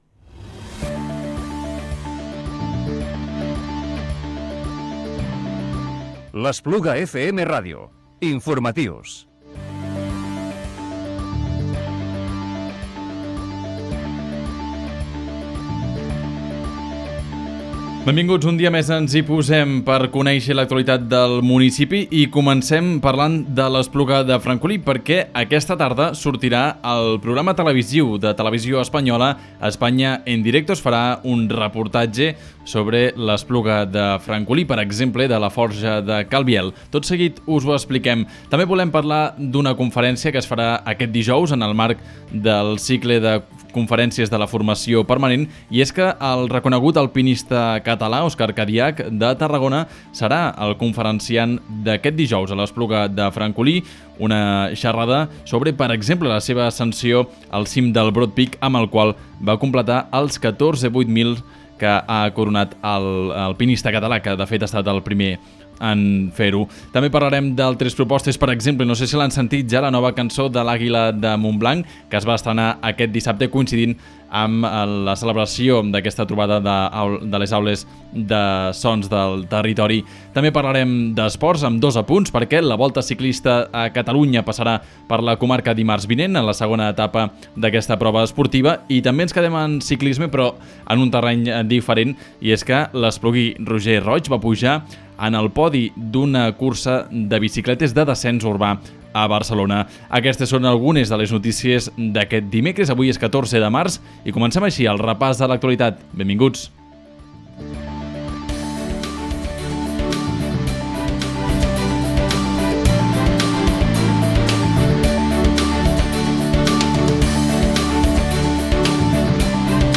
Informatiu Diari del dimecres 14 de març del 2018